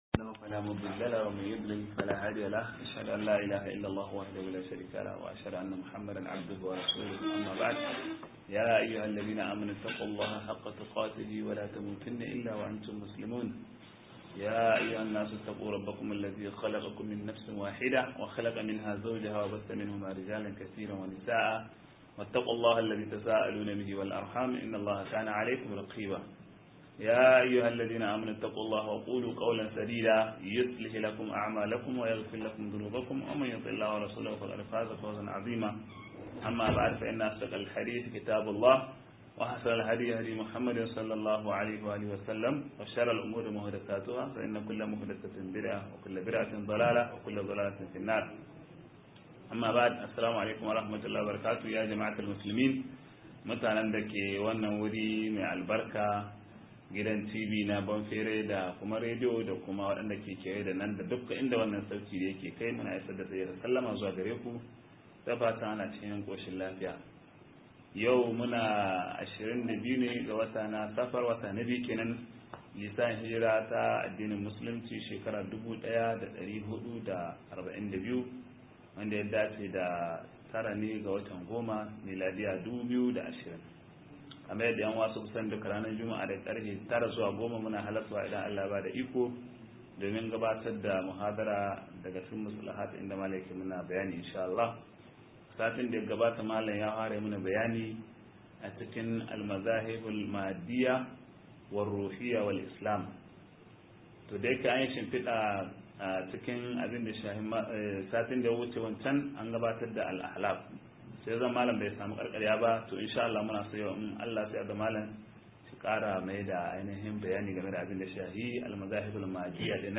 83 - MUHADARA